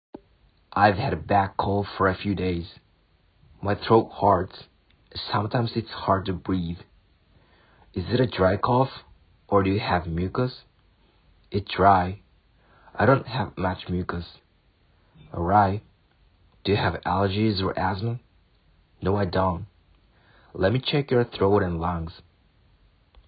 各レベルのモデル音声を掲載しますので、レベル選びや提出時の参考になさってください。
どれも音声変化や発音、リズムを意識した音声となっております。
A, Bと人によって声色を変えてくださる方もいらっしゃいます。